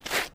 MISC Concrete, Foot Scrape 10.wav